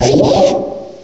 sovereignx/sound/direct_sound_samples/cries/accelgor.aif at master